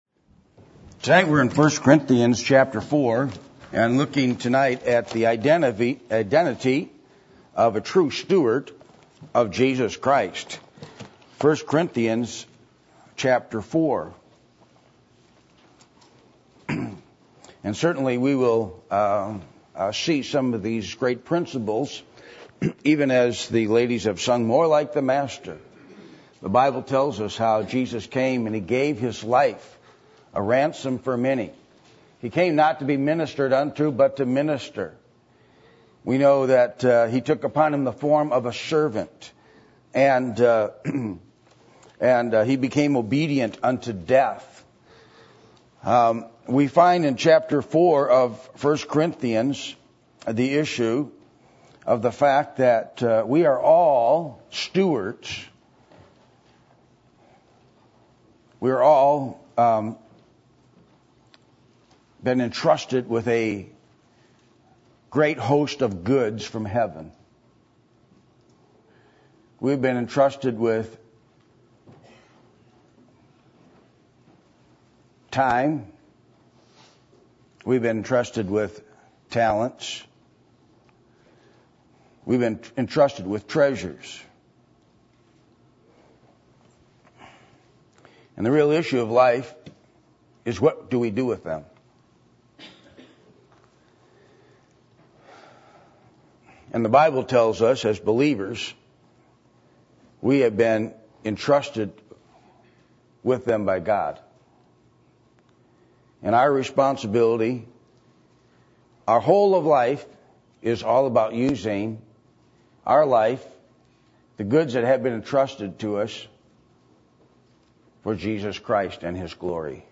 Passage: 1 Corinthians 4:8-17 Service Type: Sunday Evening